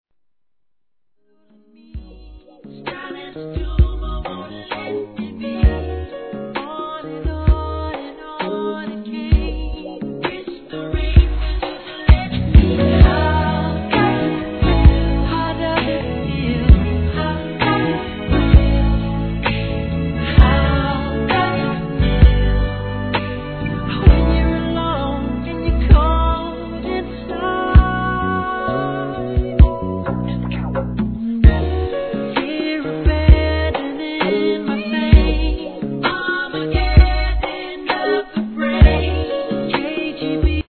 HIP HOP/R&B
しっとりと歌い上げたバラード調のGOODミディアム!!